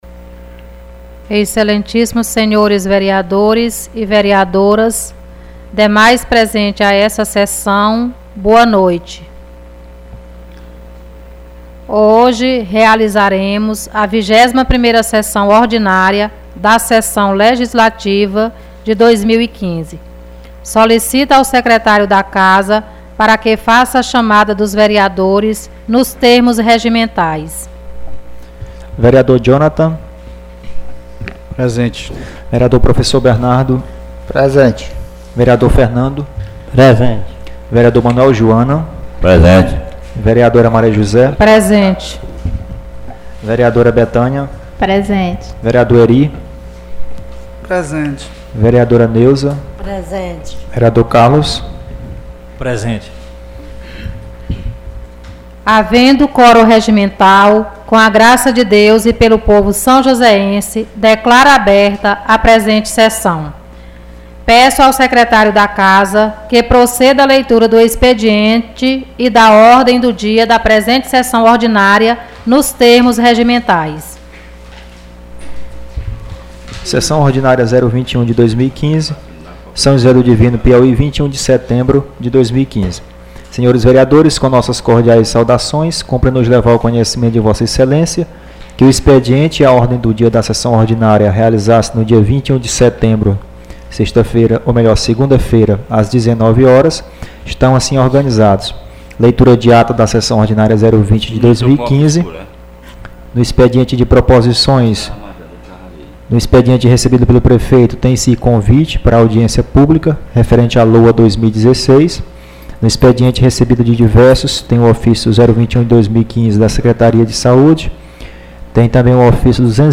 Sessão ordinária 21/2015